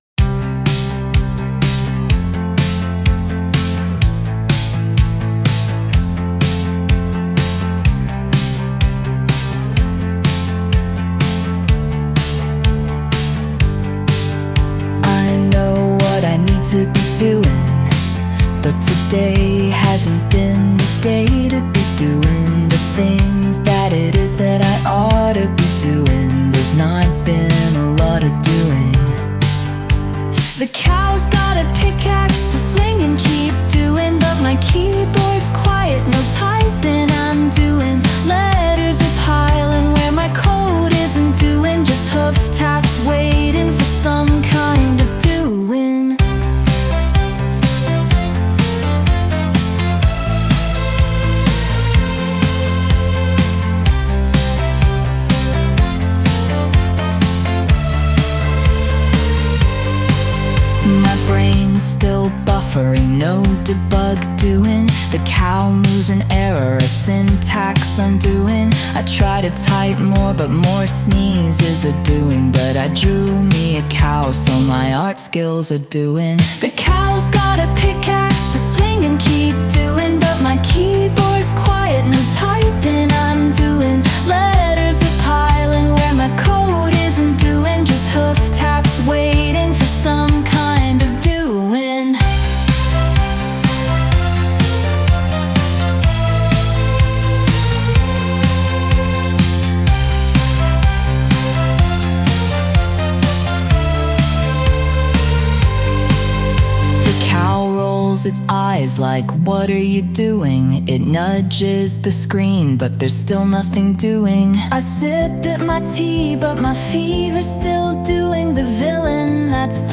Sound Imported : Knitted Reptile
Sung by Suno